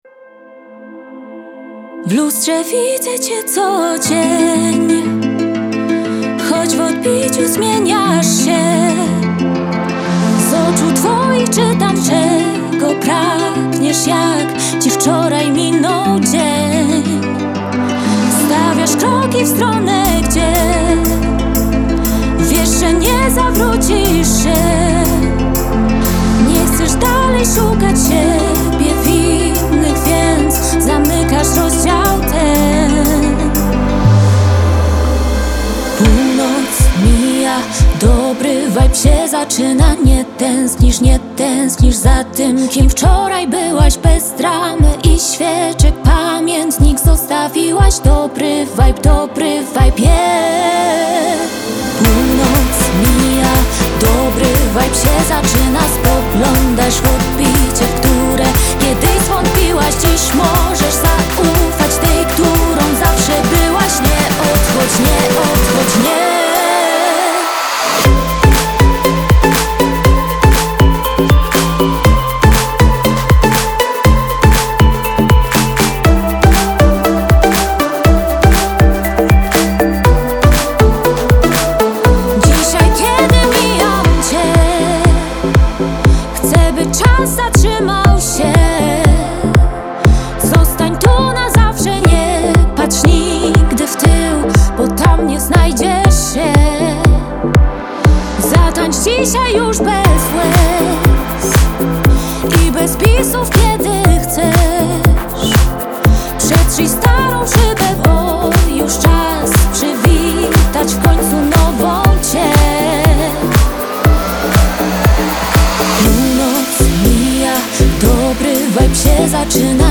Singiel (Radio)